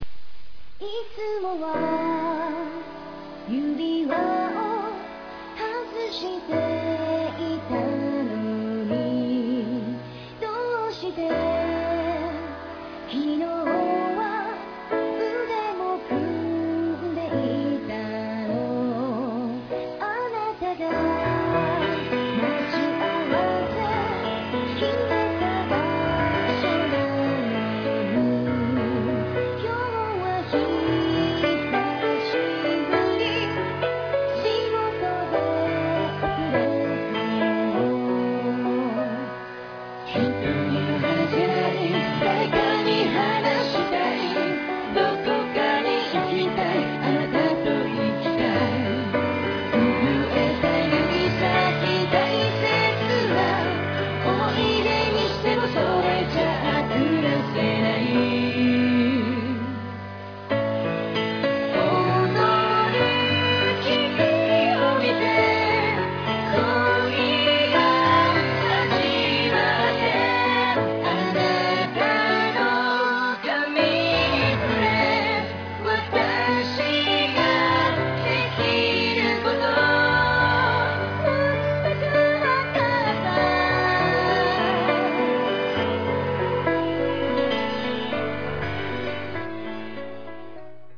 ( 主唱兼鍵盤 )
( 女主唱 )
( RAPPER )